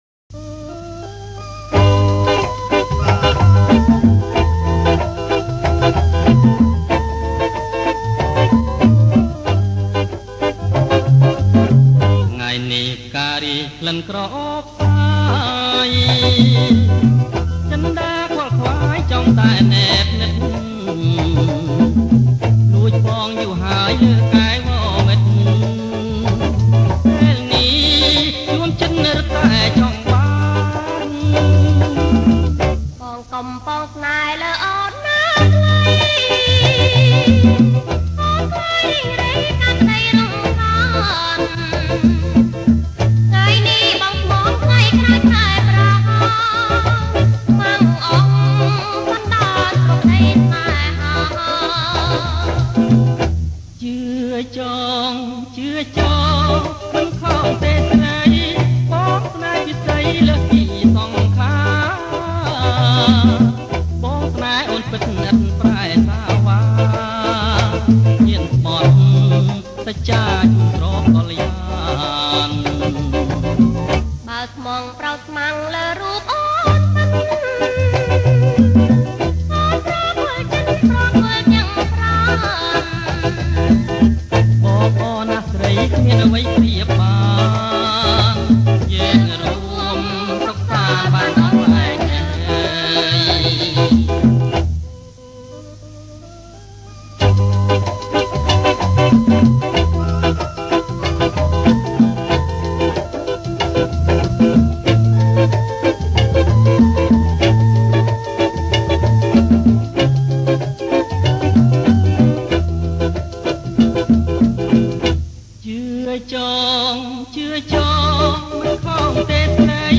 ប្រគំជាចង្វាក់  Bossa nova